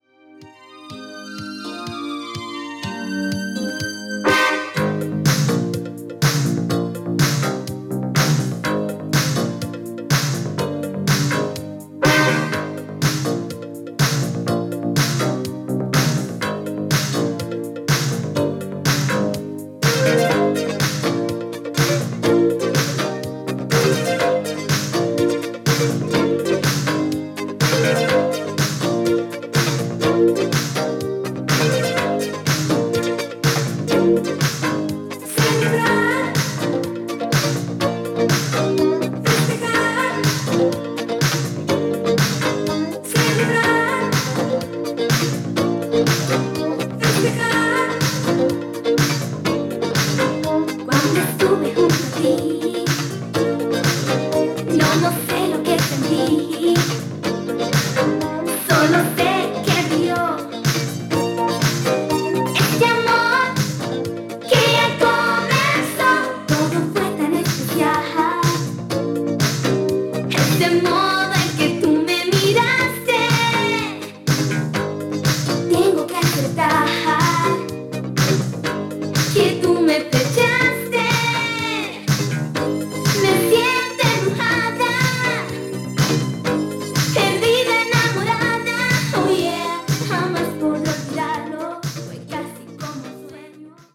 (細かな擦れ、大きな擦りありますので試聴でご確認下さい)